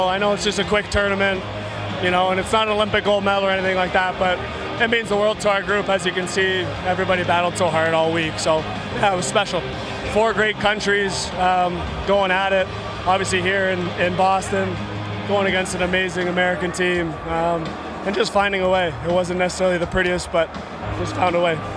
Following the victory, McDavid spoke about Canada’s win.